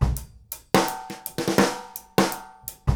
GROOVE 180HR.wav